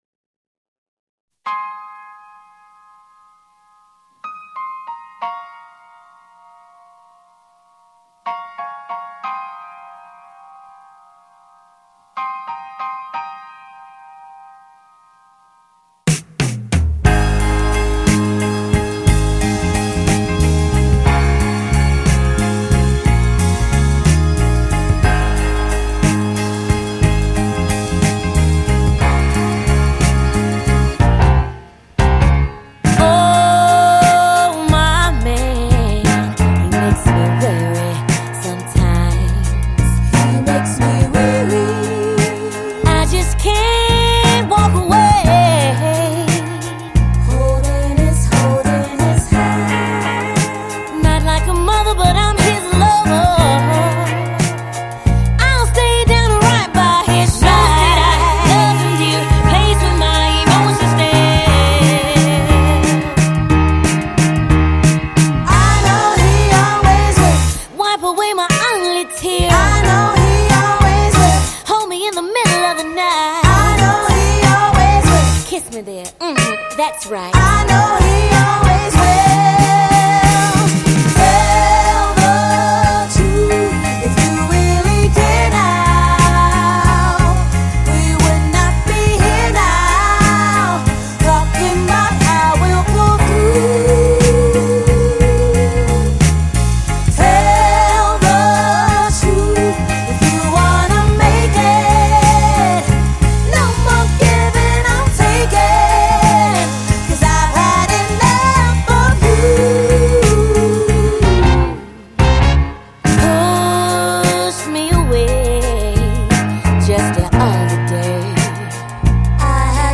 They sound decidedly a part of contemporary R&B style.